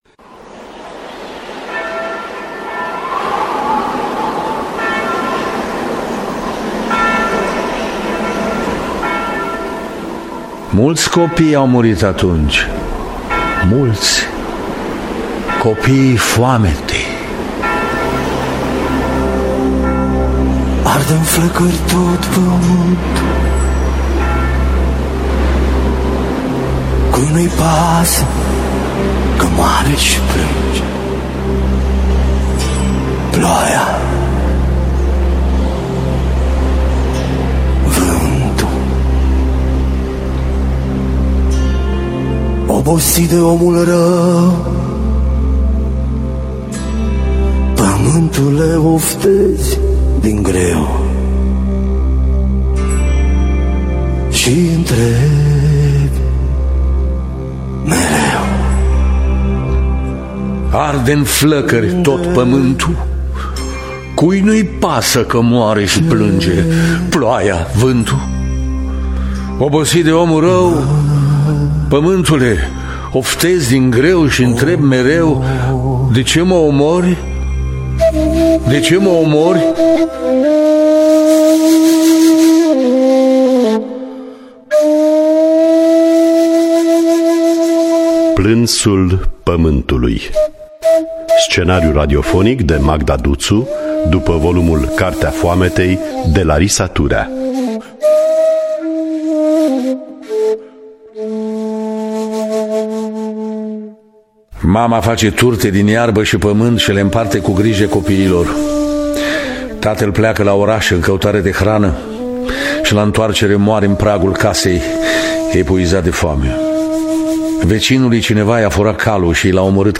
Biografii, memorii: "Cartea foametei" de Larisa Turea. Partea I. Plânsul pământului.